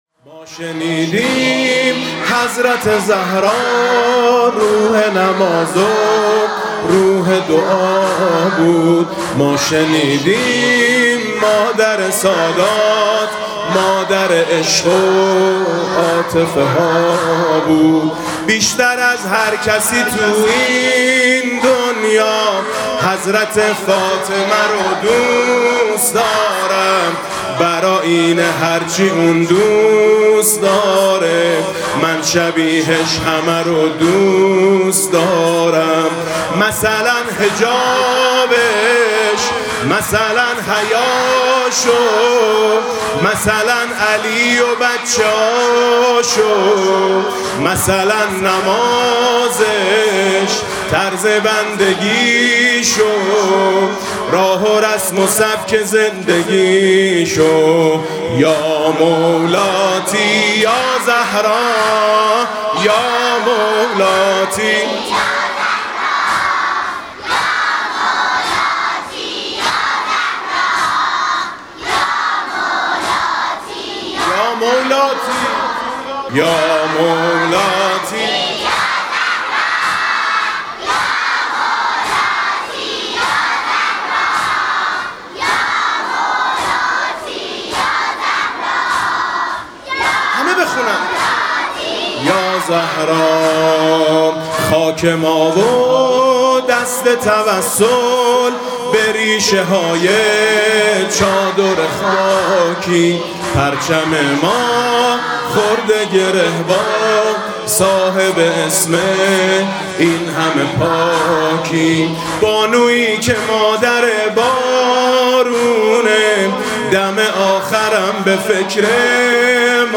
هیأت دخترانه به مناسبت عزاداری ایام شهادت حضرت زهرا (س) و استغاثه طلب باران (قرائت دعای ۱۹ صحیفه سجادیه)
با مداحی: حاج میثم مطیعی
هم‌خوانی دختر خانم‌ها با حاج میثم مطیعی: مادر عشق و عاطفه‌ها